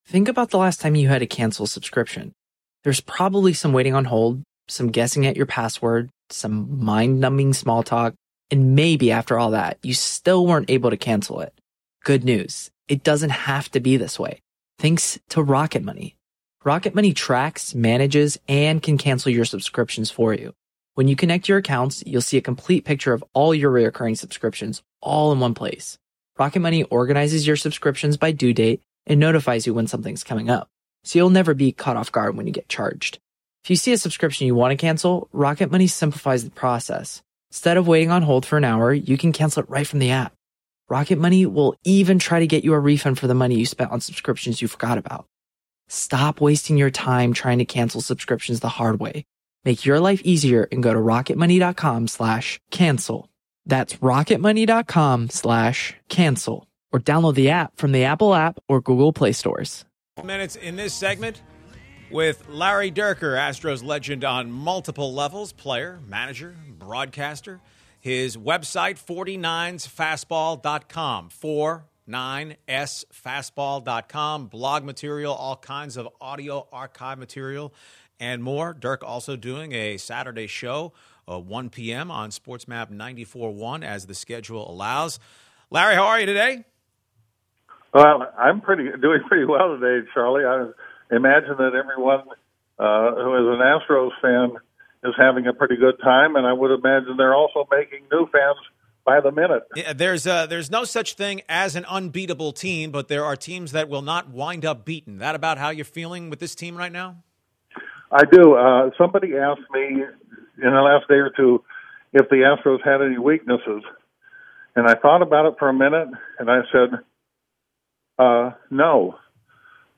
10/09/2018 Interview with Larry Dierker